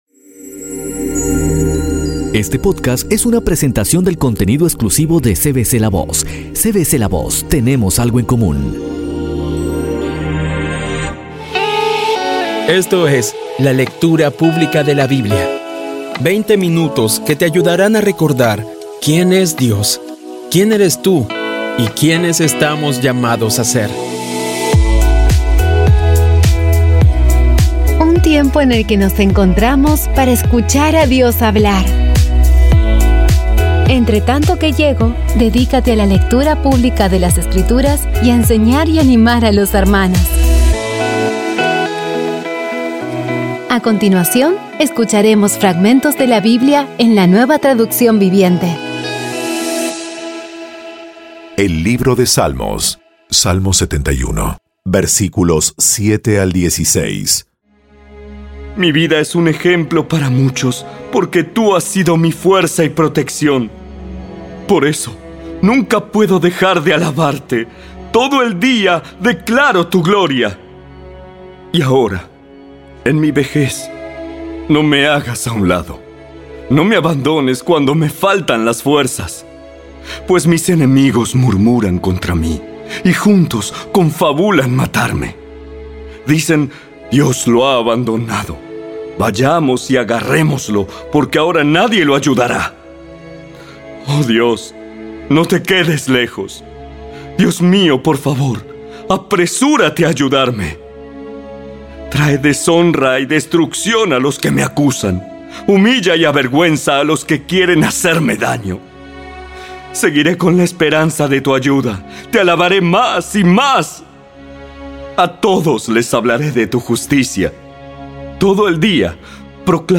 Audio Biblia Dramatizada Episodio 163
Poco a poco y con las maravillosas voces actuadas de los protagoni...